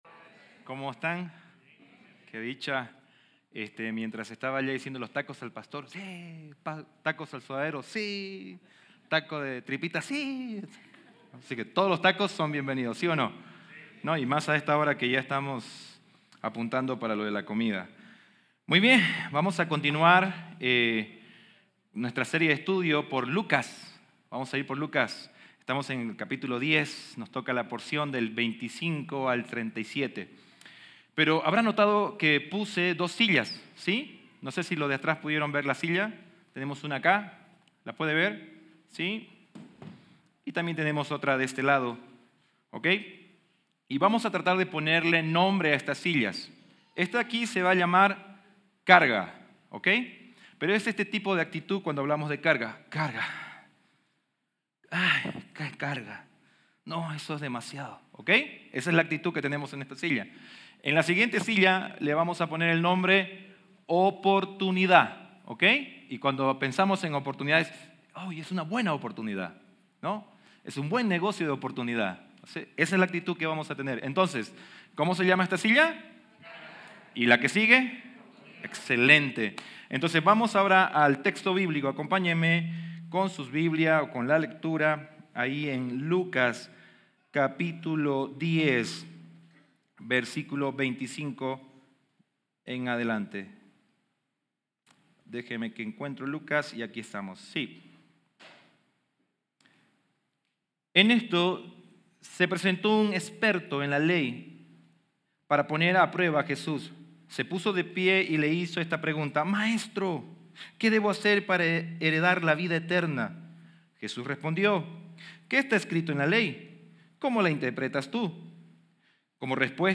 Play Rate Listened List Bookmark Get this podcast via API From The Podcast Cada semana nos reunimos en nuestra iglesia en Querétaro, Mexico para celebrar a Jesús y lo que ha hecho por nosotros.